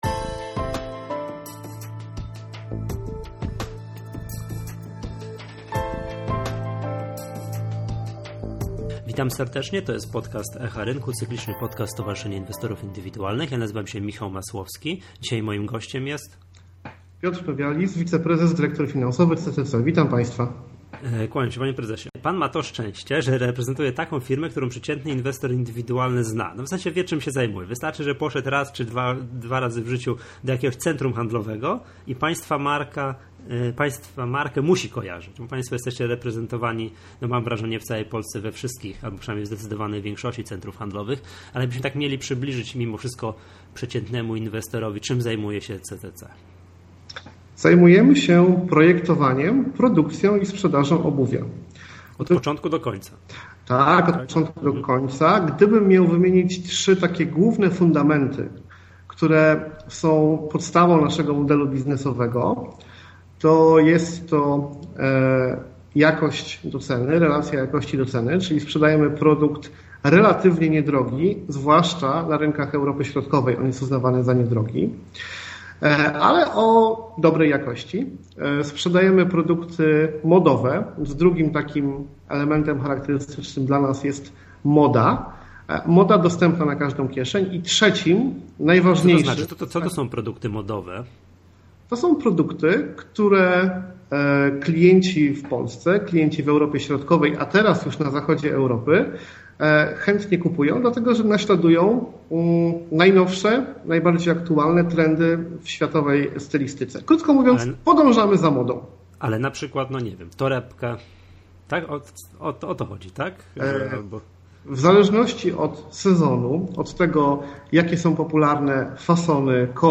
Wszystko o działalności spółki CCC w krótkim wywiadzie